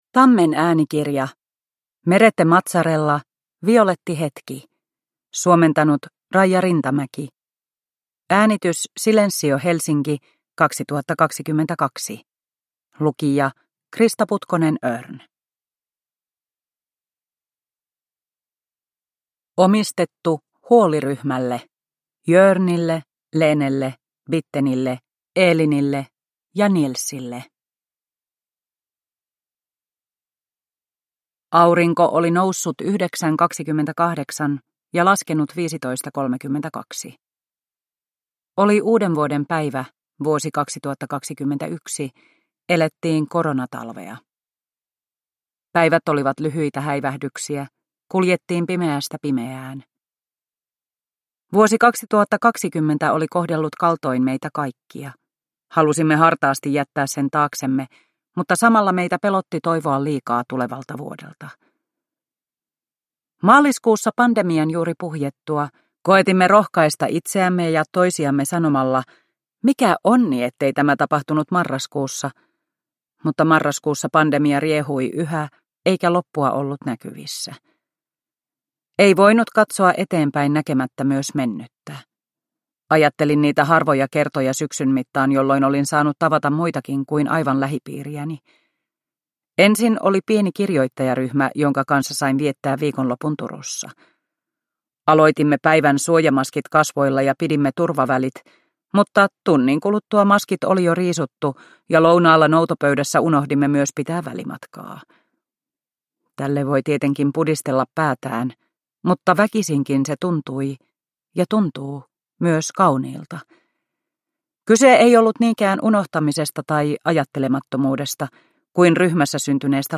Violetti hetki – Ljudbok